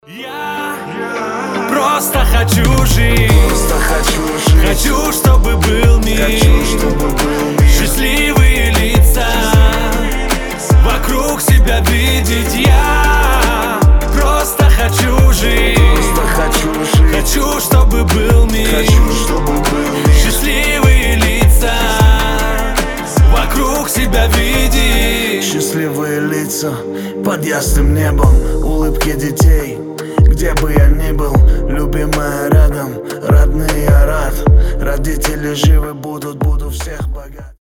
дуэт
добрые
мирные